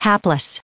added pronounciation and merriam webster audio
879_hapless.ogg